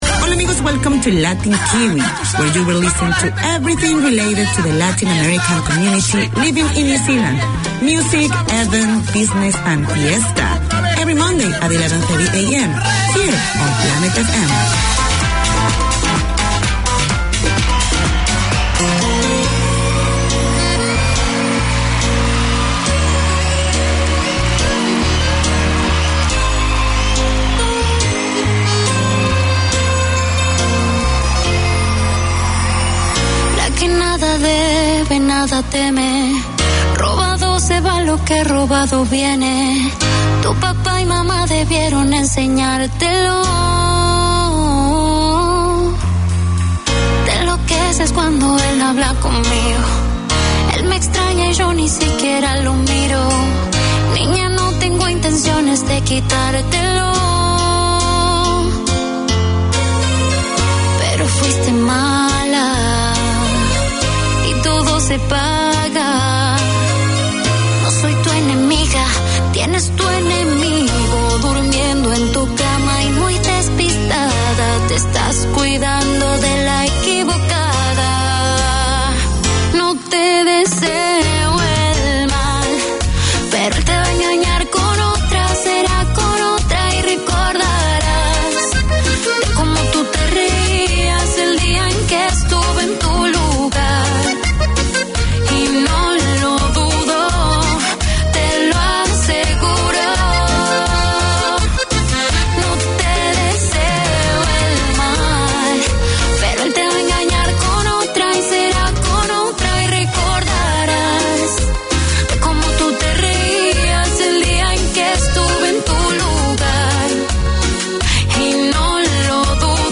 Radio made by over 100 Aucklanders addressing the diverse cultures and interests in 35 languages.
Latin Kiwi 4:25pm WEDNESDAY Community magazine Language: English Spanish Bienvenidos a todos!